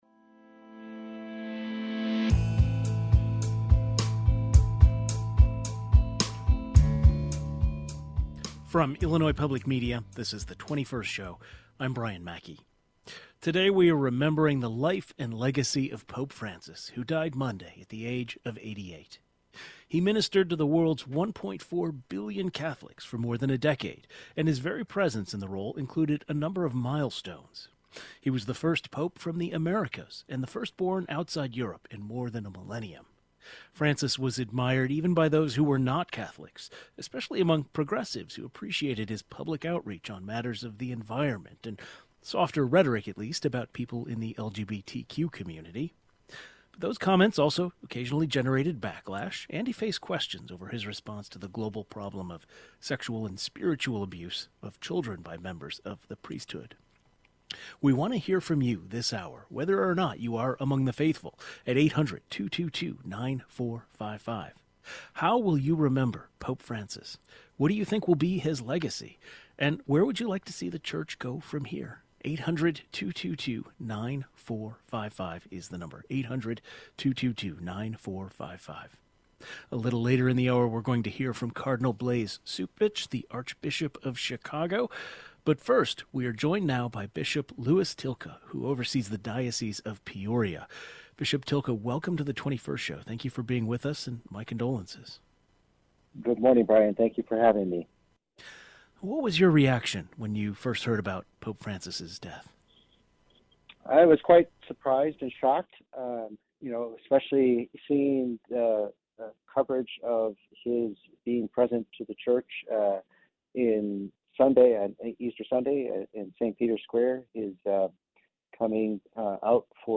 Pope Francis also deeply advocated for the poor and marginalized. A bishop from Peoria had the opportunity to meet the pope in 2022 and he joins the program today.